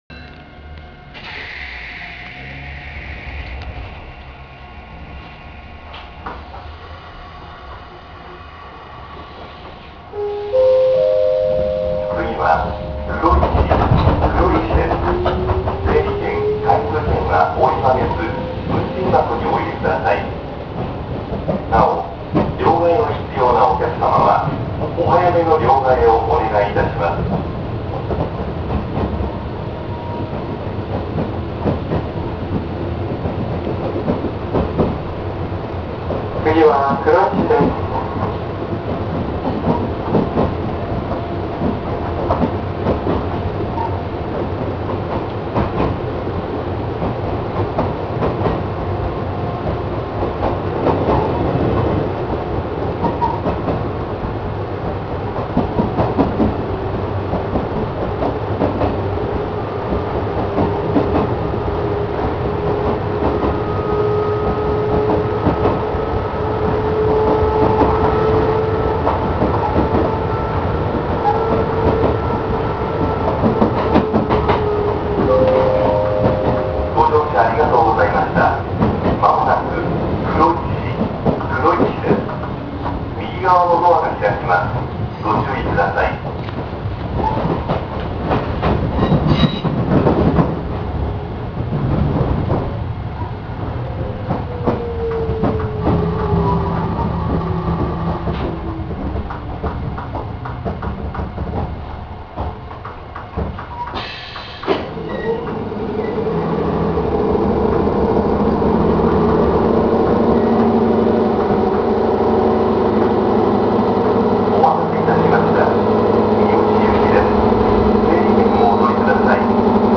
〜車両の音〜
・6000系走行音
【菊池線】三ツ石〜黒石（2分00秒：652KB）…6111A-6118Aにて
基本的に三田線時代と変わっていないはずですが、当時の音をもう殆ど覚えていないので何とも言えないのが…。ワンマン運転のため、自動放送が設置されており、地方私鉄では珍しい男声放送となっています。ドアチャイム等は特に無いみたいです。ＣＰが作動するとどうしようもなく耳障りなのも、古い車両故でしょう。